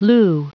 Prononciation du mot lieu en anglais (fichier audio)
Prononciation du mot : lieu